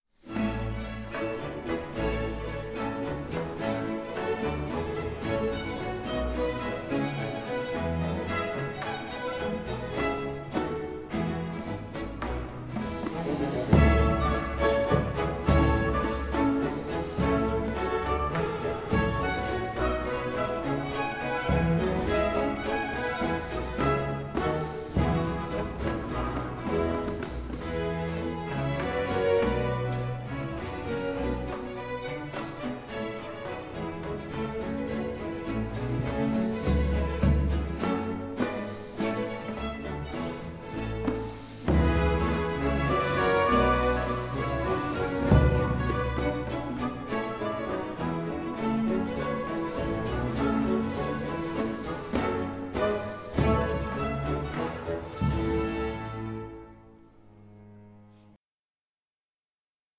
ORIENTAL BAROQUE OPERA